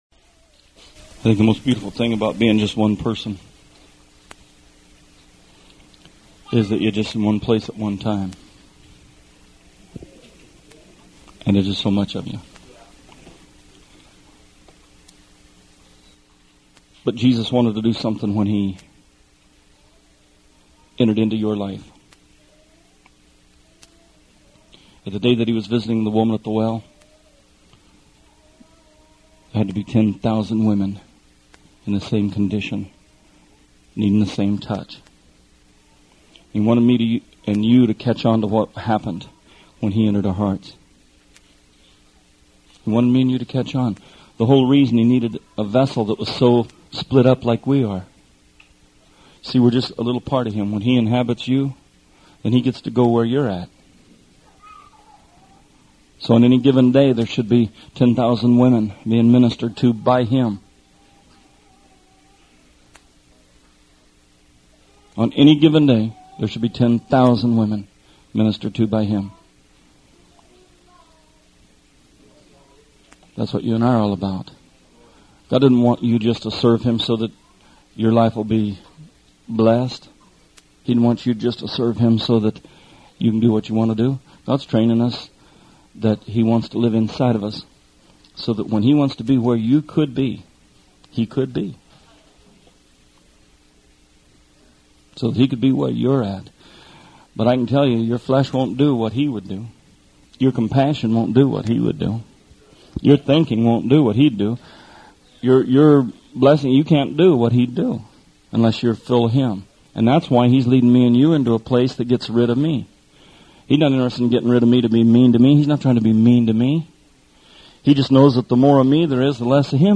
Songs of Degrees: This is a sermon series about Psalms 120 through 134.